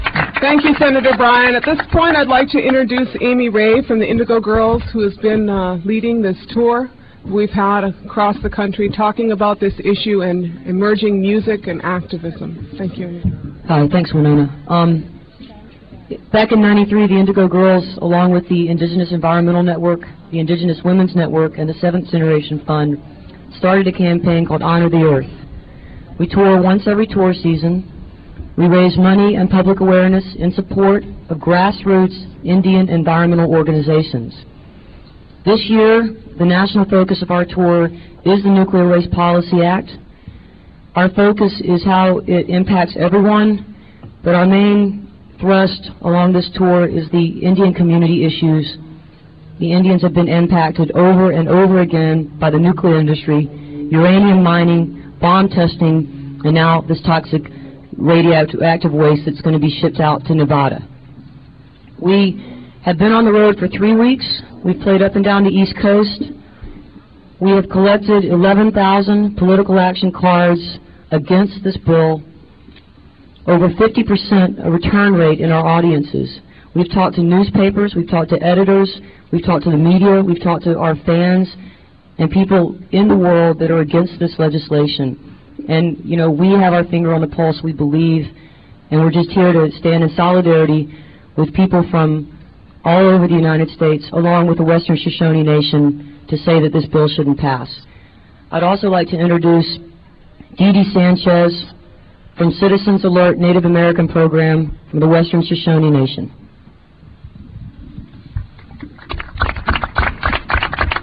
lifeblood: bootlegs: 1997-09-24: honor the earth press conference - washington, d.c.
06. press conference - amy ray (1:51)